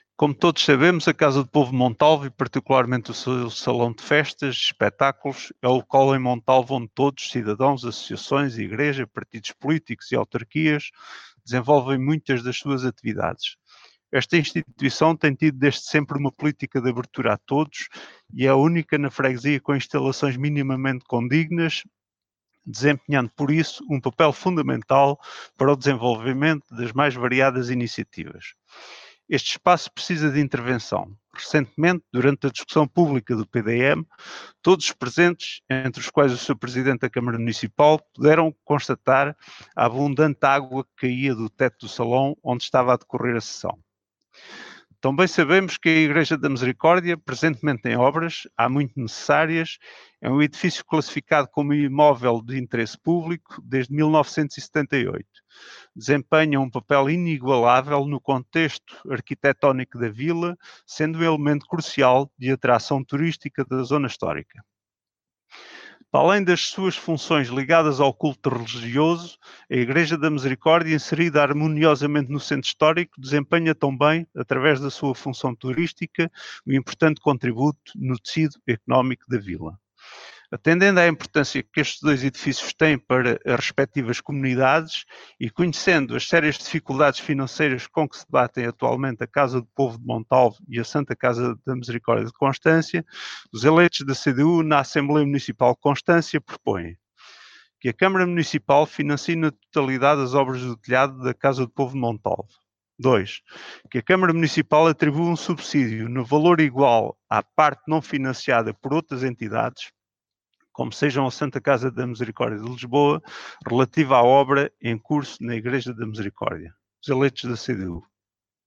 “São necessidades extremas de edifícios que estão permanentemente abertos à população, têm uma importância muito grande para as localidades e é neste sentido que veio esta recomendação”, explicou o deputado municipal Rui Ferreira (CDU) em sessão da Assembleia Municipal de Constância.